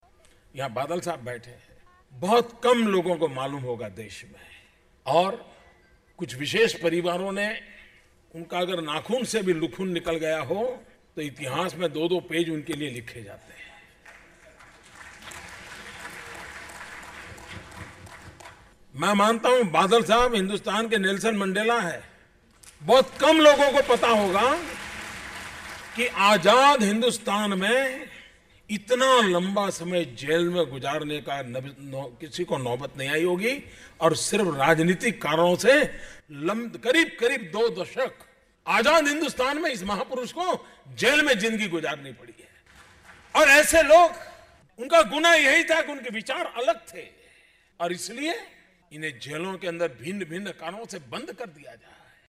प्रधानमंत्री नरेंद्र मोदी ने दिल्ली में एक कार्यक्रम में कहा कि 'अकाली दल के नेता प्रकाश सिंह बादल भारत के नेल्‍सन मंडेला हैं.'उन्होंने कहा कि बादल 'वो महान नेता हैं जिन्हें आज़ाद हिंंदुस्तान में संघर्ष करते हुए भिन्न कारणों से क़रीब दो दशक जेल में रहना पड़ा.'